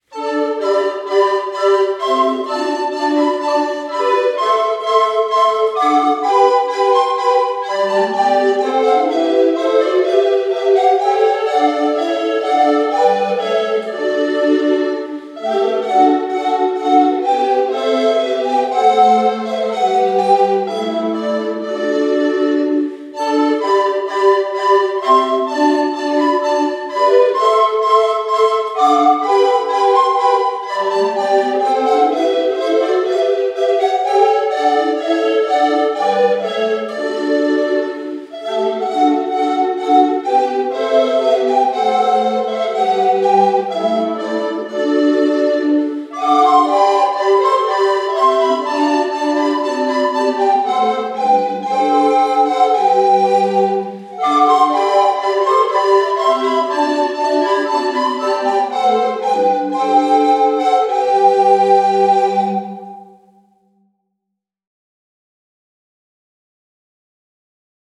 „The Fairy Queen“ von Purcell, als Blockflötenquartett bearbeitet, lag plötzlich in meinen Händen.
an einem recht heißen Samstag unsere Blockflötenquartette für das Klassenspiel und auch die Fairy Queen professionell aufnehmen zu lassen.